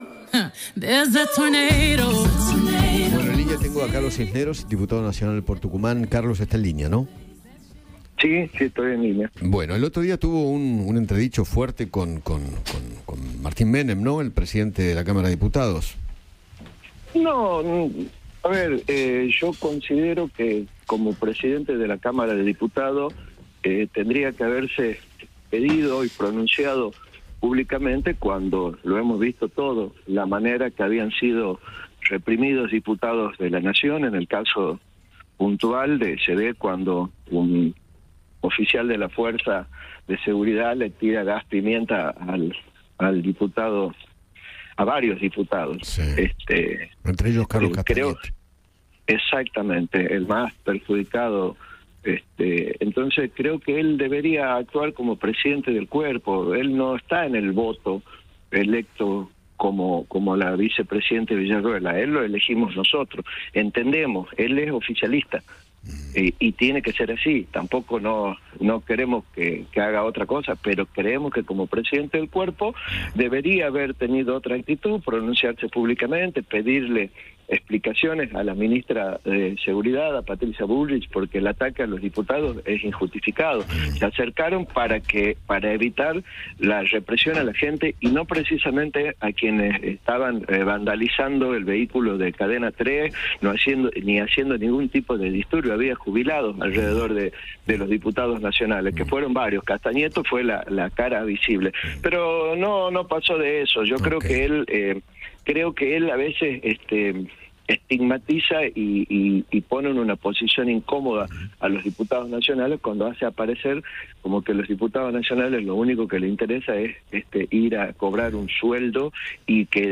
El diputado nacional por Tucumán, Carlos Cisneros, habló con Eduardo Feinmann sobre el impacto del impuesto a las ganancias en algunos gremios y se refirió al cruce que mantuvo con Martín Menem.